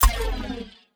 BoopIn.wav